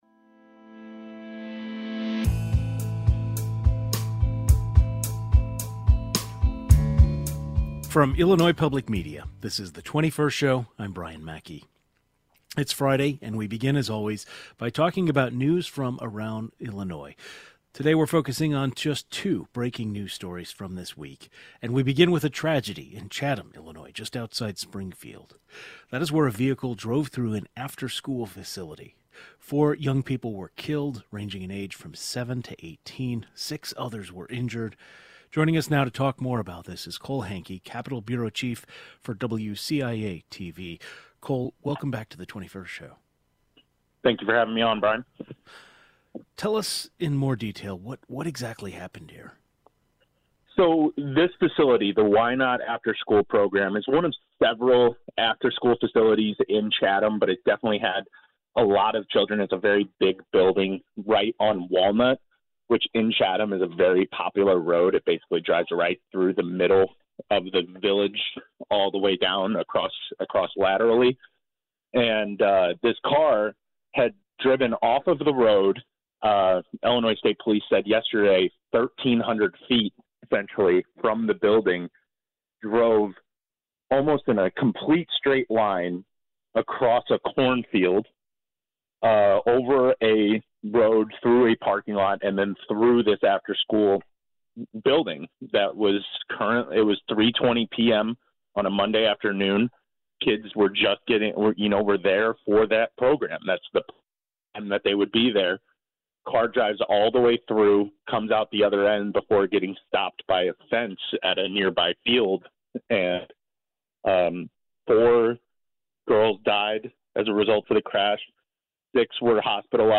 It's Friday, which means it's time for our Reporter Roundtable where we talk about stories making headlines in different parts of the state. We start in Chatham, south of Springfield, where a vehicle drove through an after-school facility and killed four children between the ages of 7 and 18 and injured six others. Plus, we get an update on a shooting that took place on the Illinois State University campus.